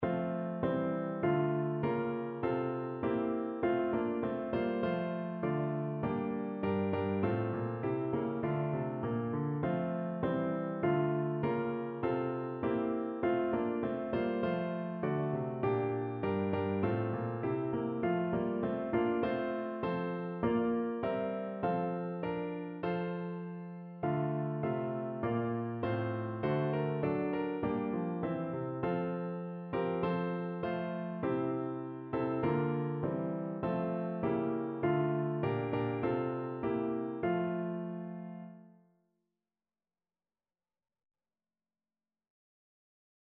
No parts available for this pieces as it is for solo piano.
Moderato
4/4 (View more 4/4 Music)
Piano  (View more Intermediate Piano Music)
Traditional (View more Traditional Piano Music)